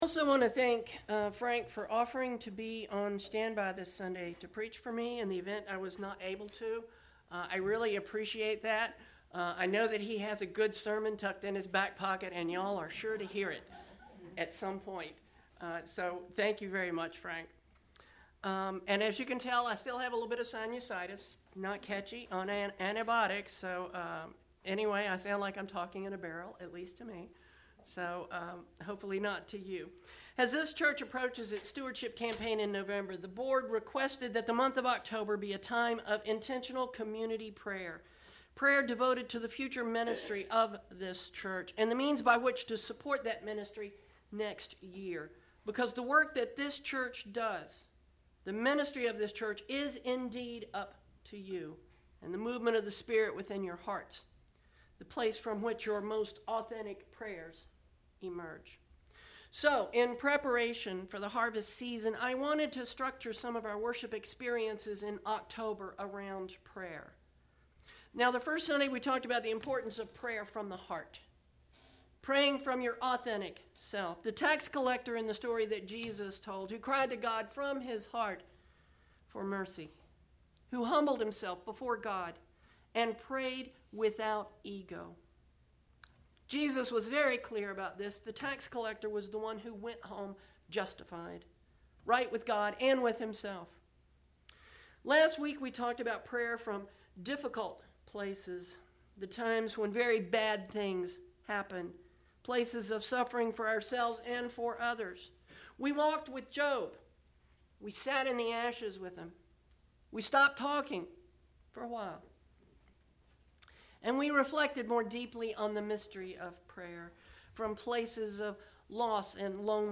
Pentecost 19 at MCC NoVA – Oct15 |
10/15 Sermon Posted